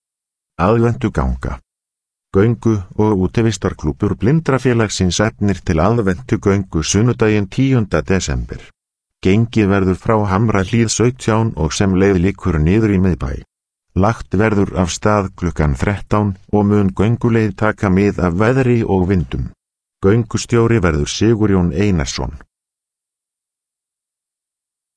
Hljóðritað hjá Hljóðbók.slf í desember 2017.
Tilkynningar og fréttnæmt efni: